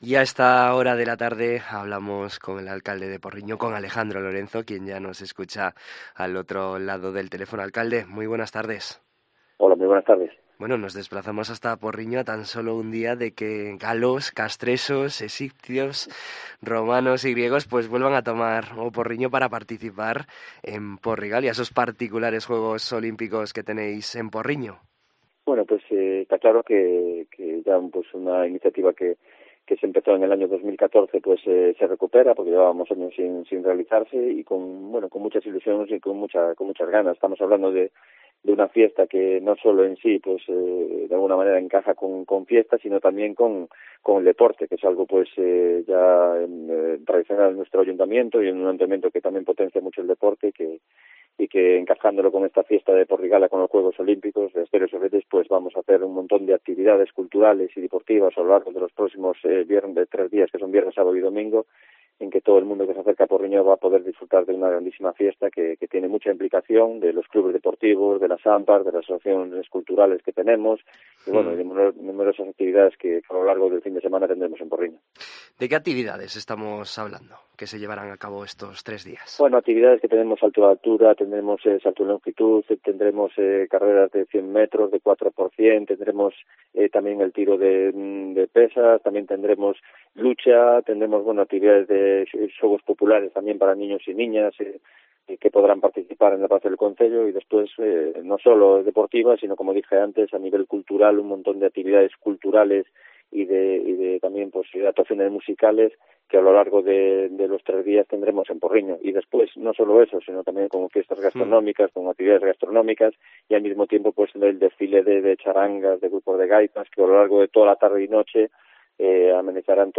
AUDIO: En COPE Vigo conocemos en profundidad este evento de la mano del alcalde de Porriño, Alejandro Lorenzo